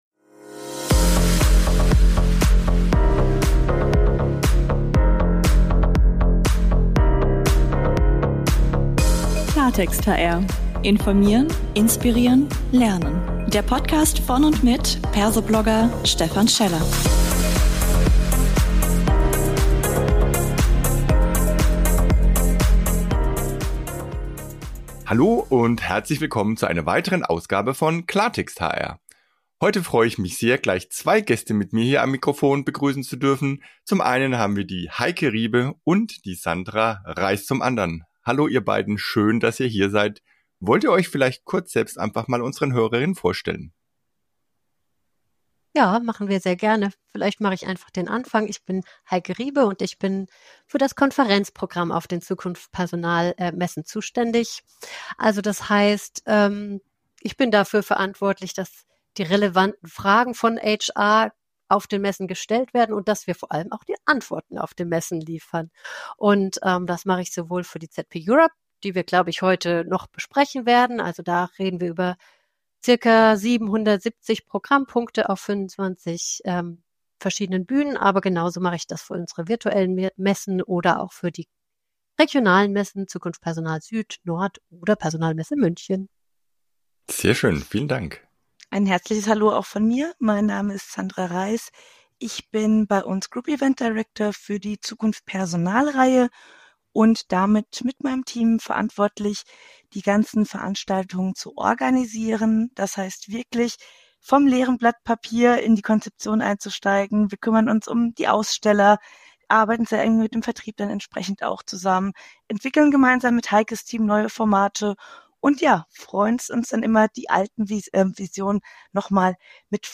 Ein spannender Talk als 20-Minuten-Impuls.